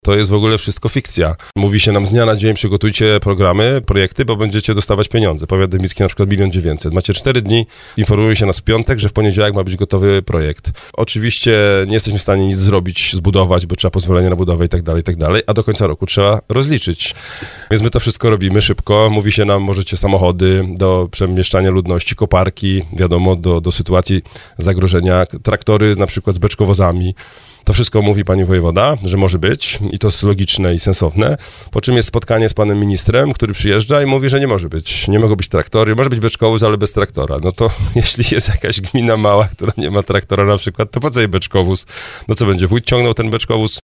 Starosta dębicki Piotr Chęciek zwracał uwagę na antenie Radia RDN Małopolska, że pojawia się bardzo dużo sprzecznych komunikatów.